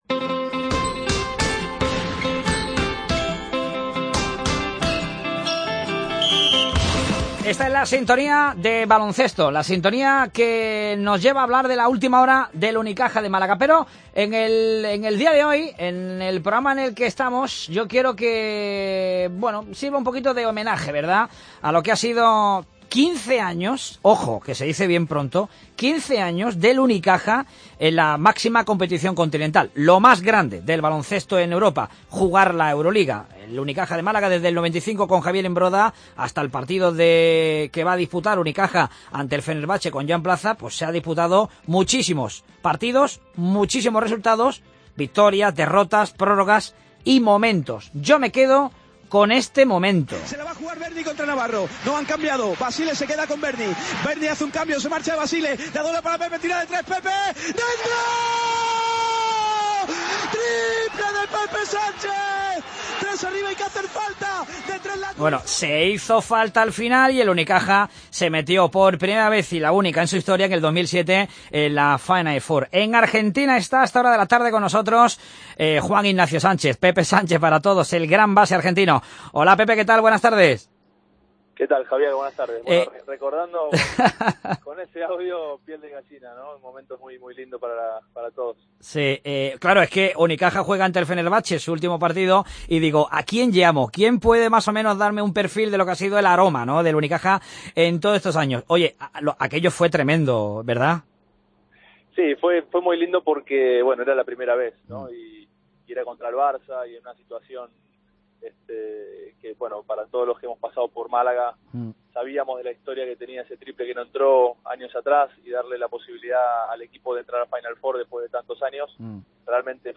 Entrevista a Pepe Sánchez (Ex-base de Unicaja) (Deportes Cope Málaga 8 Abril)
Llamada a Juan Ignacio "Pepe" Sánchez en Bahía Blanca, Argentina al que fuera base de Unicaja que con su recordado triple ante el Barcelona en el 2007 pemitió a Unicaja lograr su hito más grande, jugar la Final Four de ese año en Atenas.